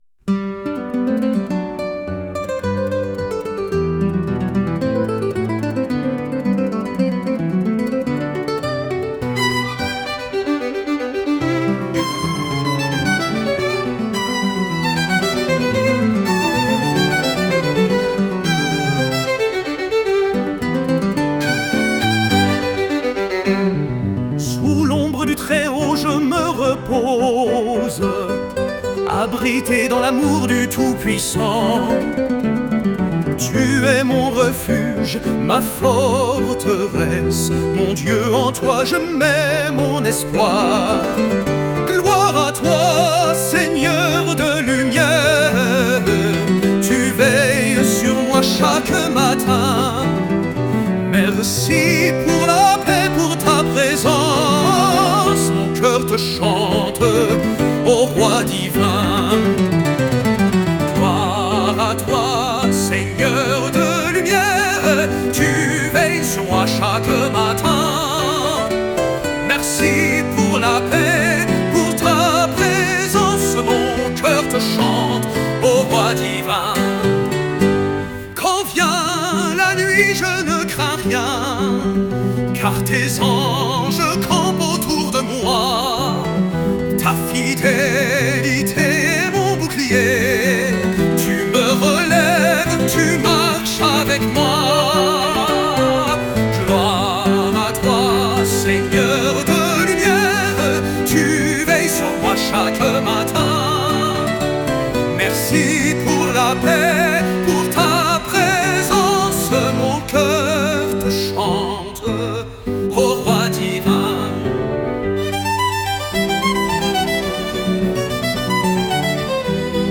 Chant : Sous l’ombre du Très-Haut